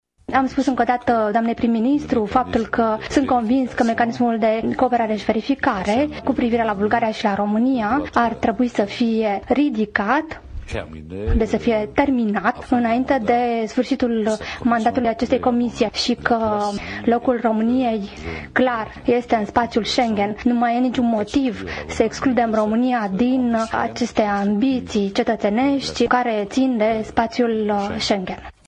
Precizările au fost făcute miercuri seară, la Bruxelles, într-o conferinţă de presă comună cu premierul Viorica Dăncilă.